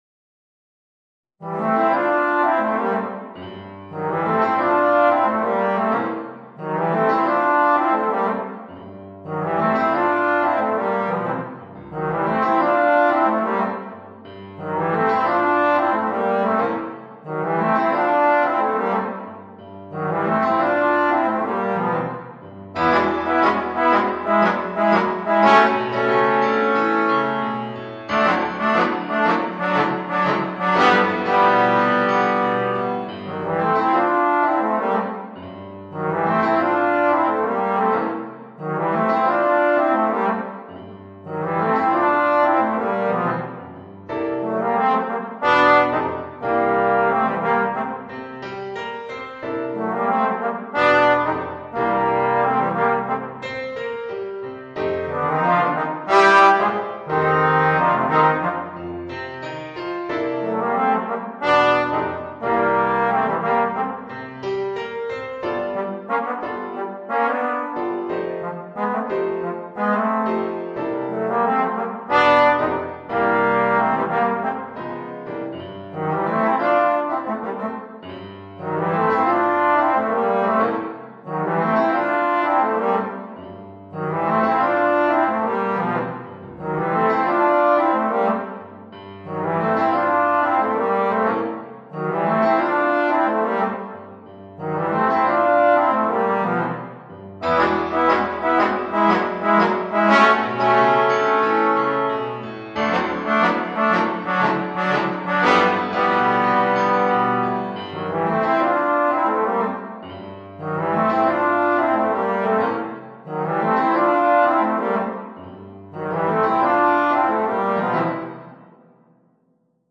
Voicing: 2 Trombones w/ Audio